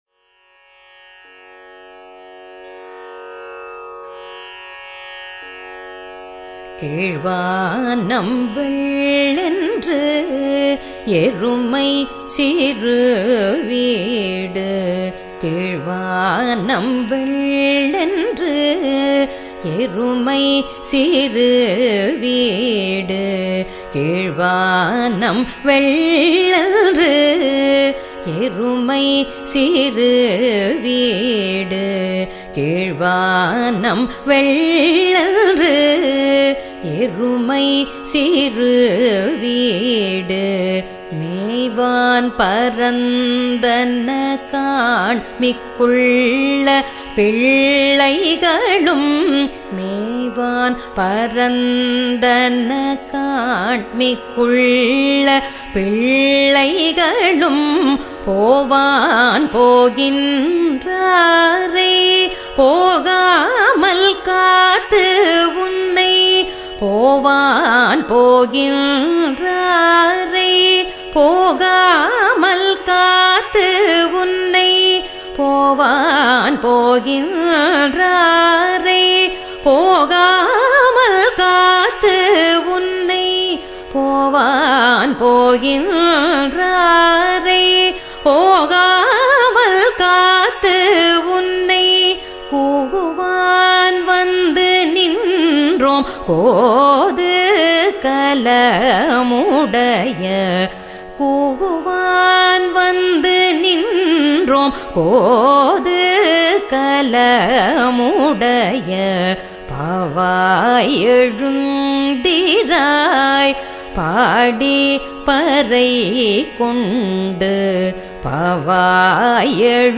dhanyAsi
khanda chAppu